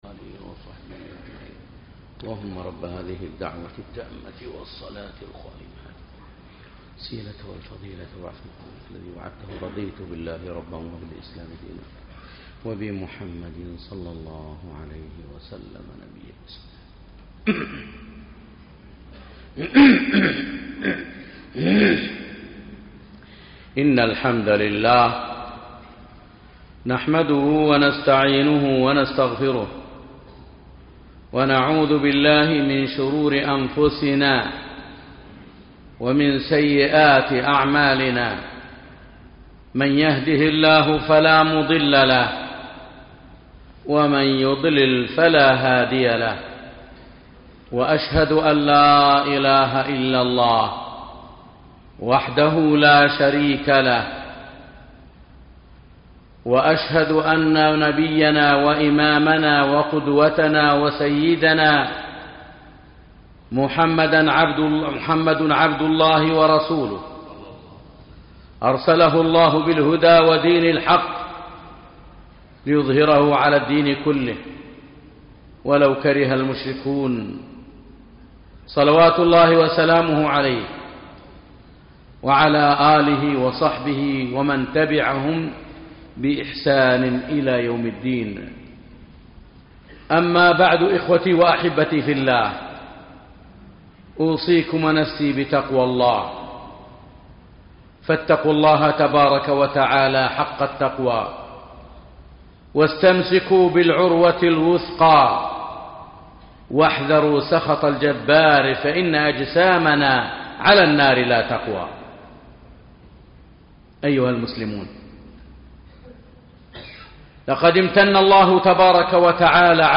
أهمية التوحيد - خطبة